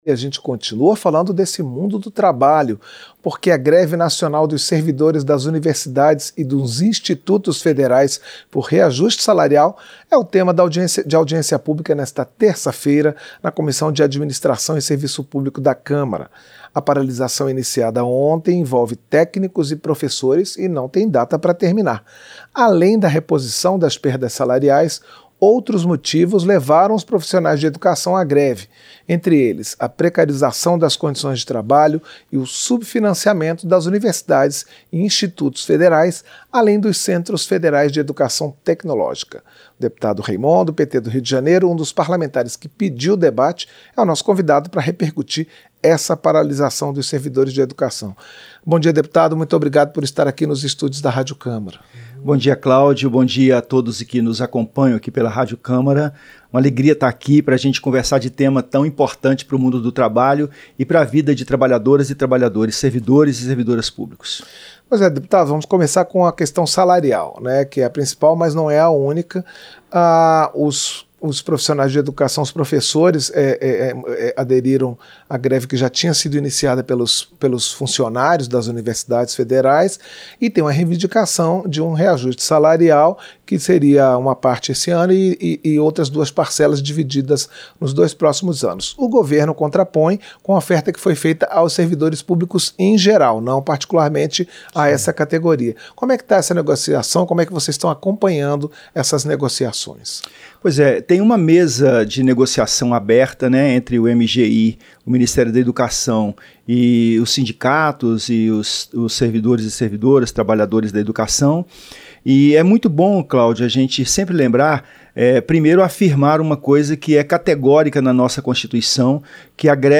Entrevista - Dep. Reimont (PT-RJ)